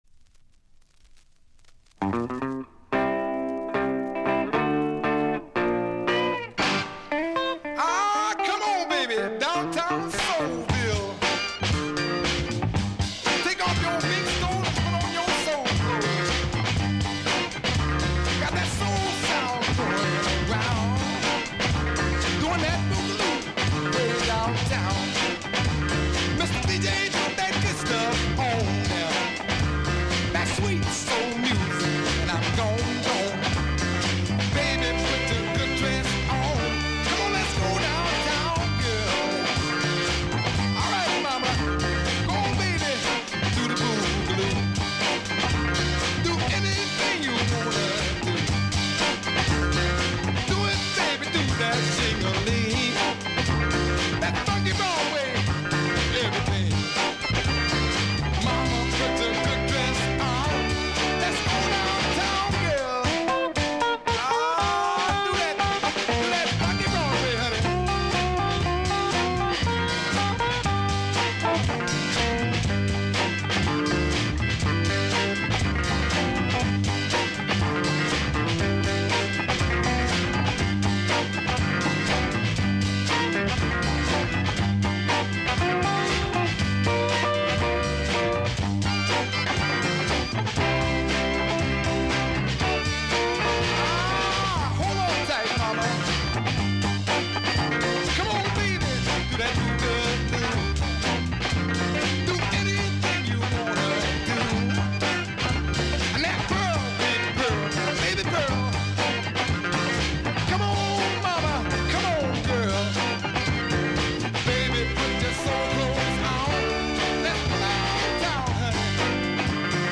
Music behind DJ